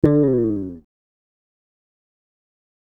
D LONG FALL.wav